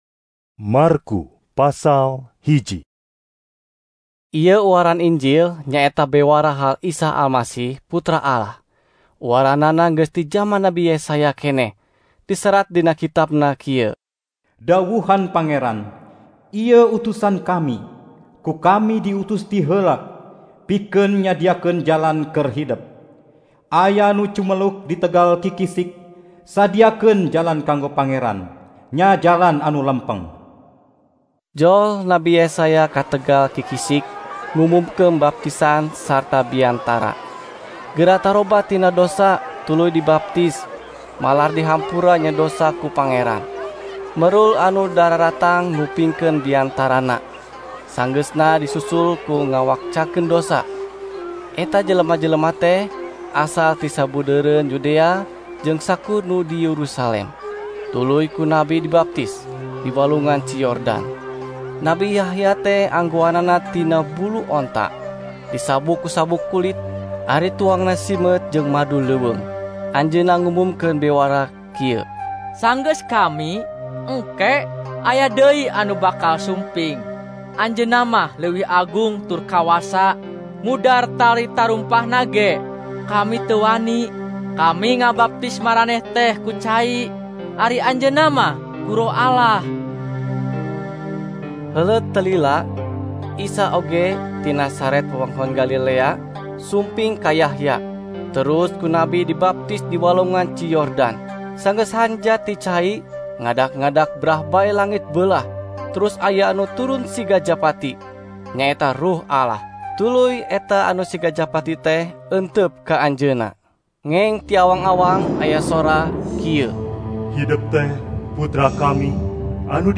NT Drama